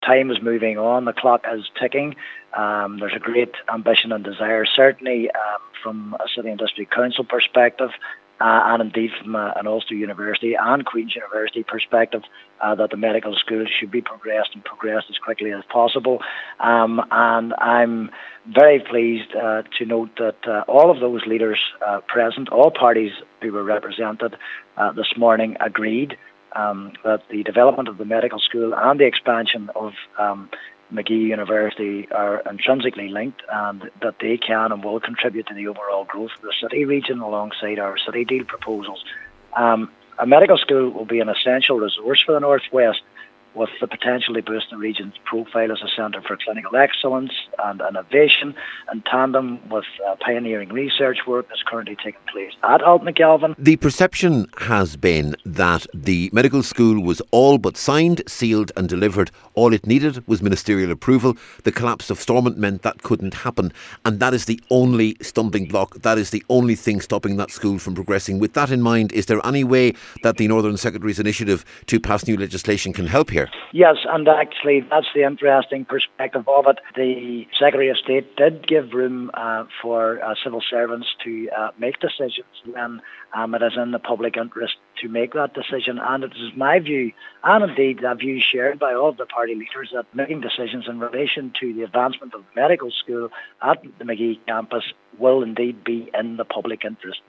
Mayor John Boyle says recent attempts by the Northern Secretary to address the consequences of the Stormont impasse may provide a short term answer: